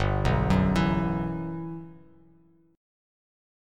GM13 Chord
Listen to GM13 strummed